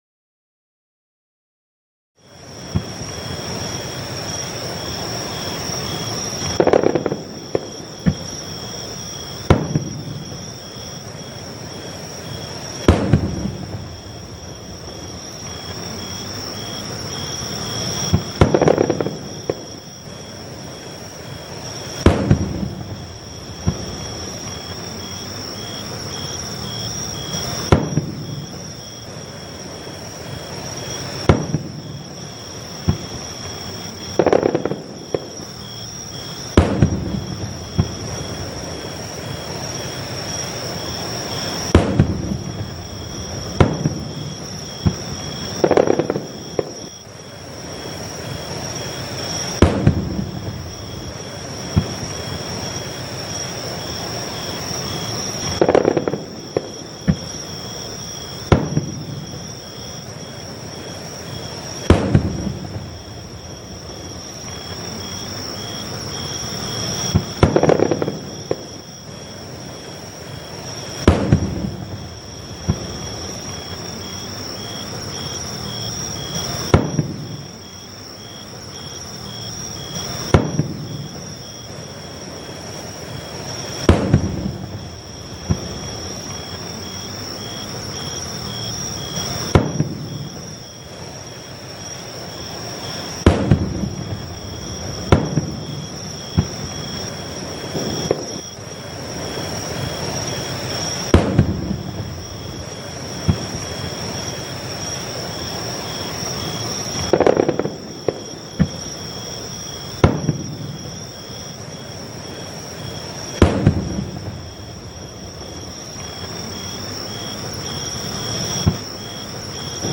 Waterfall Frequency That Triggers Your Body's Natural Healing Response
All advertisements are thoughtfully placed only at the beginning of each episode, ensuring you enjoy the complete ambient sounds journey without any interruptions.